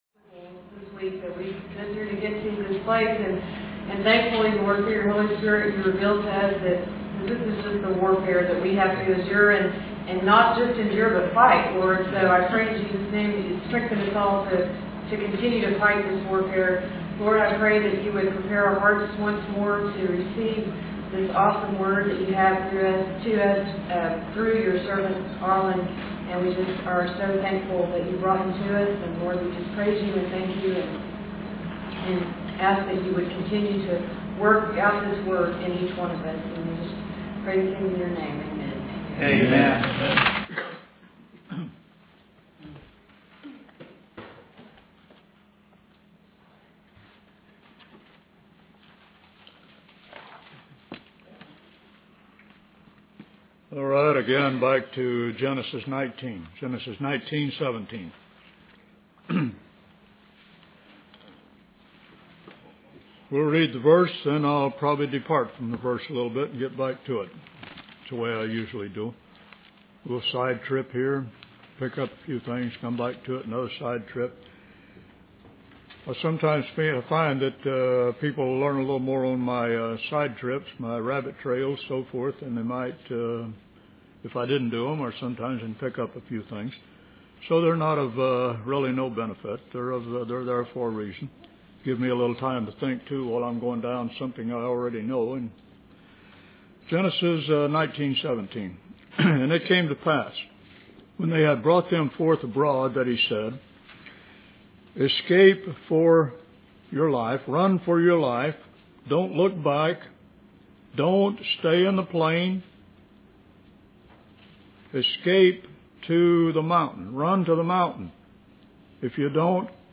In this sermon, the preacher emphasizes that believers have been saved for a purpose and that purpose extends to all of mankind. He speaks about the present kingdom of God and the future rule of man.
He concludes by inviting the congregation to stay for a meal and briefly mentions the depth of the flood in Genesis.